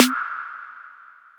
Huge Snare Single Hit E Key 07.wav
Royality free snare single hit tuned to the E note. Loudest frequency: 2840Hz
huge-snare-single-hit-e-key-07-Eml.ogg